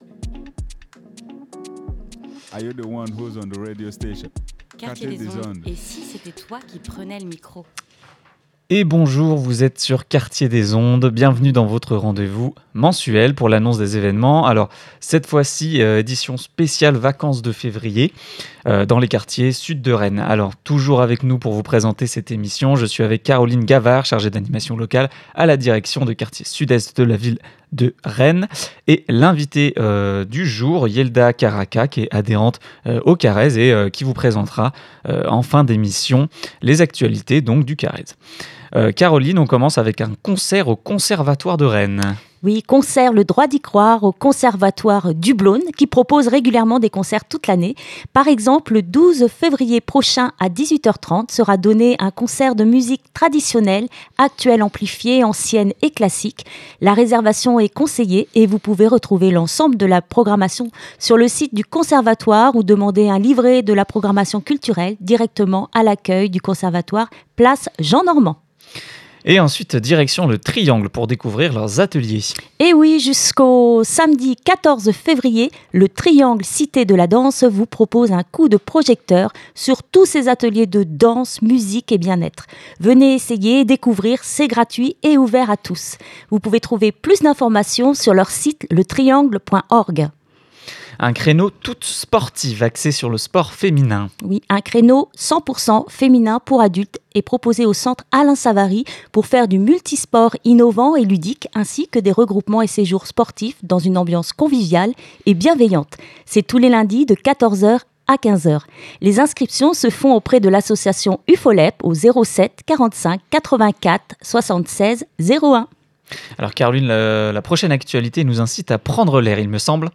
Une émission animée par